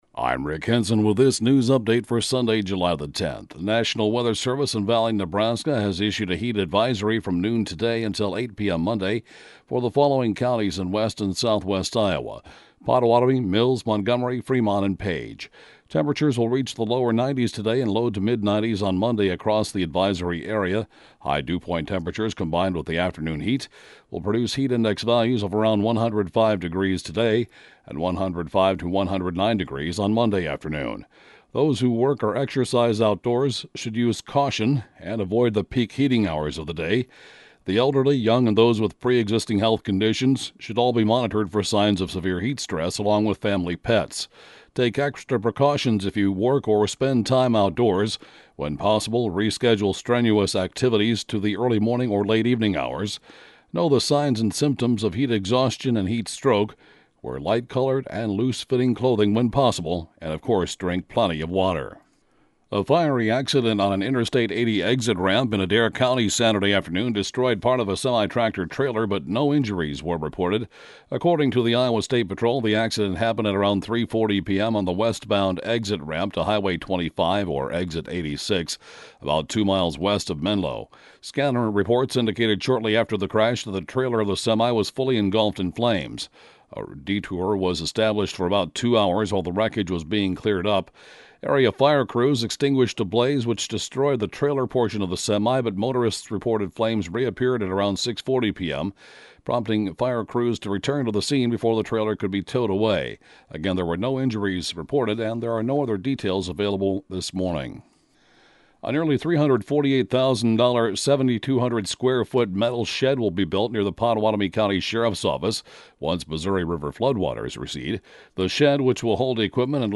News update for Sun., July 10th